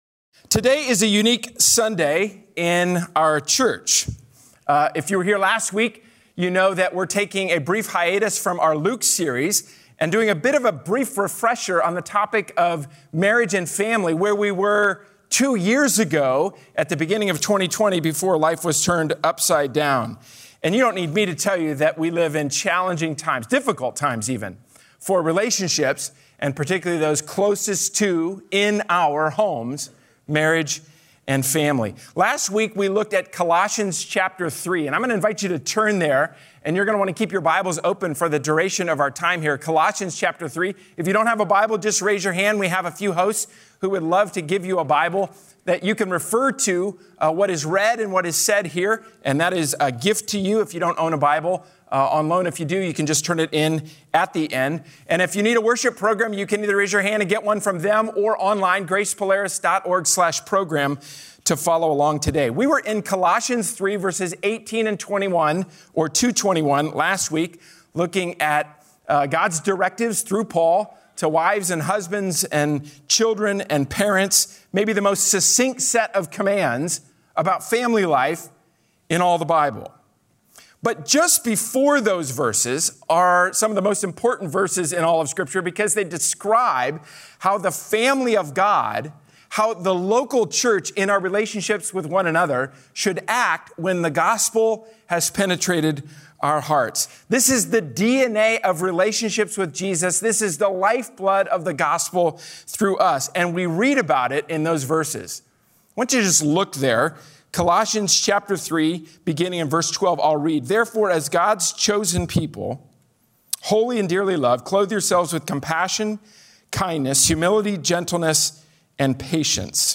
Sermon
A sermon from the series "Heaven, Help the Home!."
A panel of couples from our church family discusses the joys and difficulties that come when living out the Bible's plan for marriage.